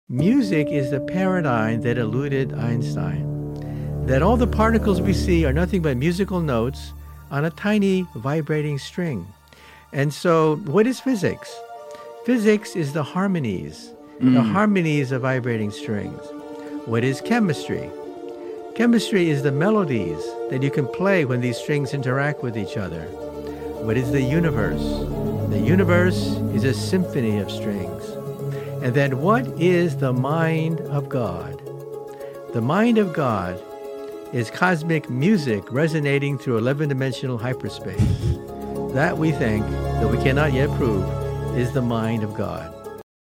Source of the video (Title on YouTube): Michio Kaku Explains Multiverse, Aliens & Quantum IMMORTALITY | 145 Summary of the Podcast: This podcast with the physicist Michio Kaku discuss three fascinating topics: the multiverse, aliens, and quantum immortality.